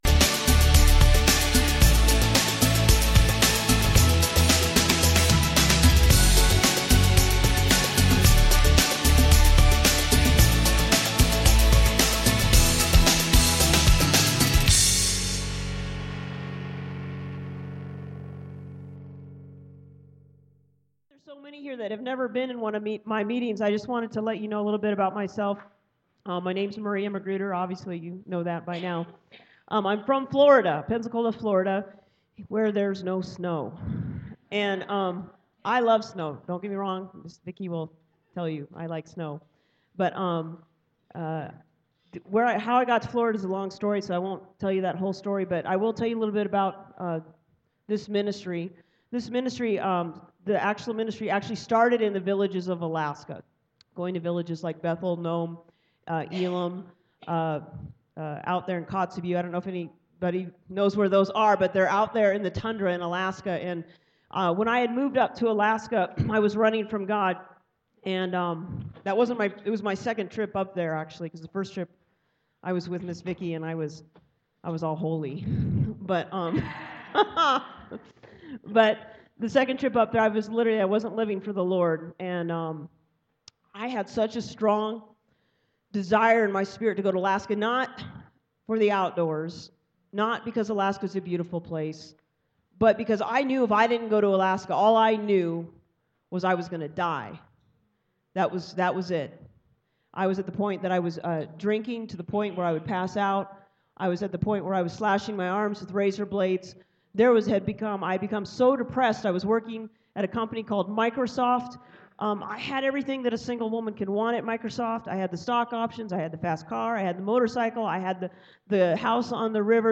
Due to the recordings not be up to the level of excellence we expected, we are giving MORE FREE DOWNLOADS for the inconvenience.